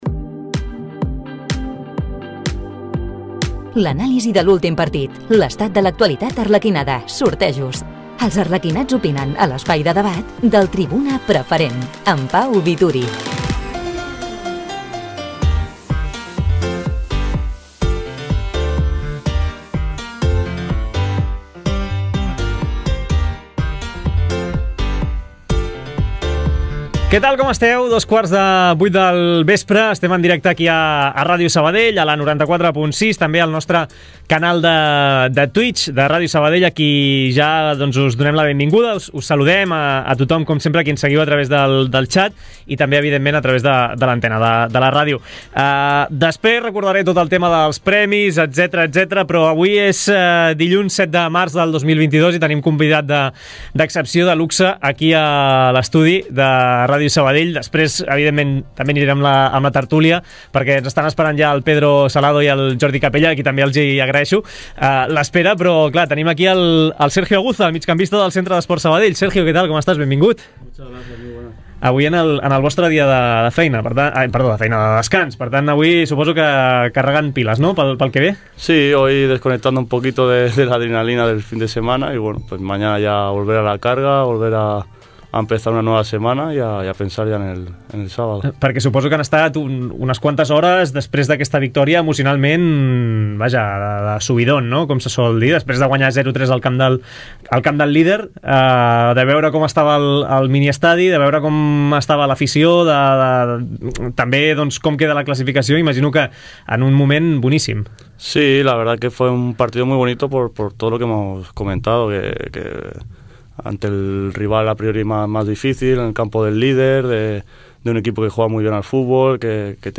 La tertúlia del Centre d’Esports Sabadell. Un espai d’opinió i debat al voltant de l’actualitat i el futur del club arlequinat. Fidel al seus orígens, el programa està obert a la participació directa dels socis, seguidors i aficionats del conjunt sabadellenc.